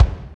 25.06 KICK.wav